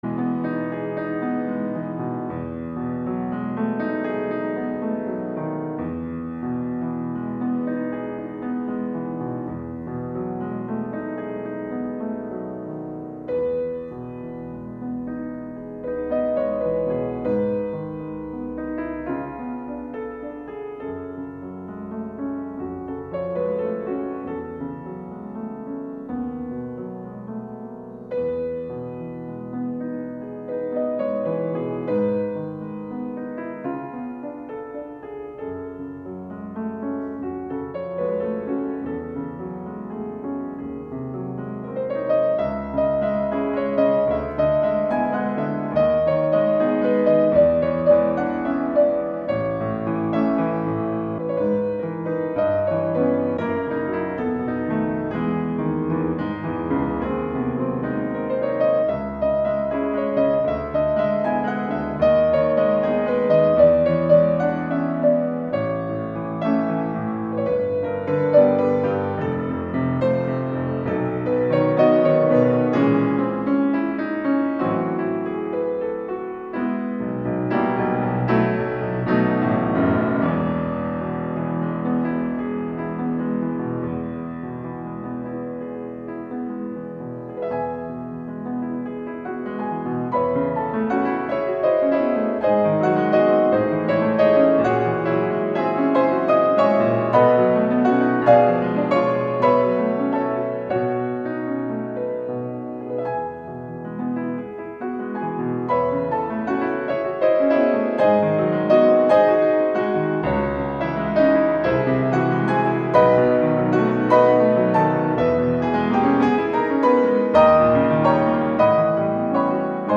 música académica costarricense